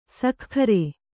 ♪ sakhkhare